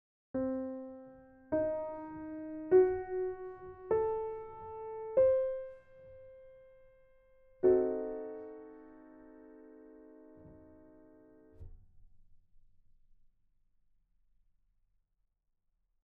A great feature of a diminished chord is that it’s completely symmetrical, dividing the octave into 4 equal parts by stacking minor 3rds.
diminished-explained.mp3